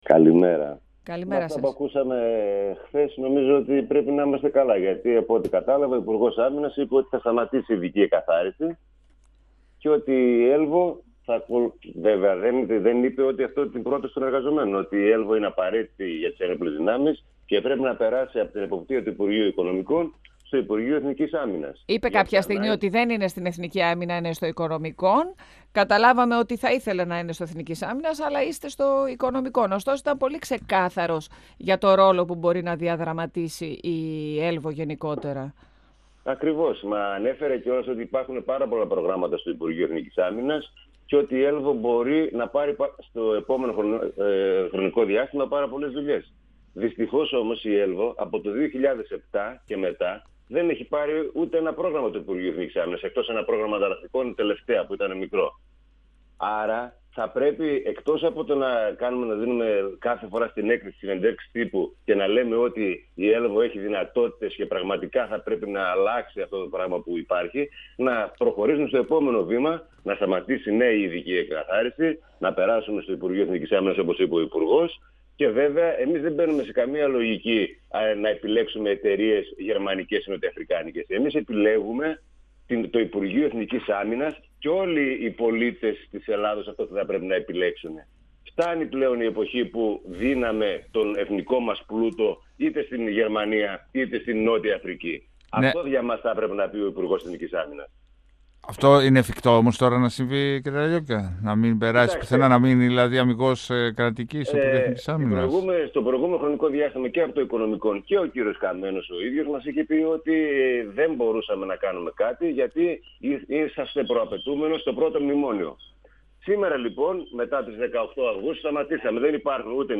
στον 102FM του Ρ.Σ.Μ. της ΕΡΤ3